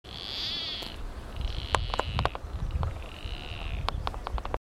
White-tipped Plantcutter (Phytotoma rutila)
Sex: Male
Life Stage: Adult
Detailed location: Río Chubut
Condition: Wild
Certainty: Observed, Recorded vocal
Cortarramas--macho.mp3